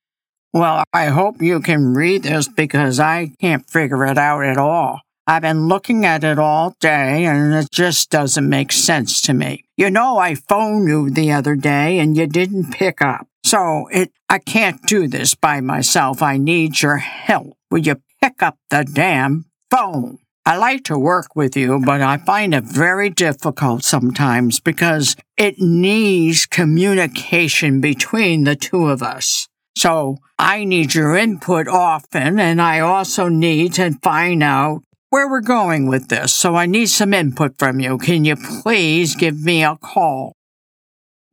Narrations are recorded with a home studio-quality MSB Mic, providing consistent and reliable performance.
Angry Man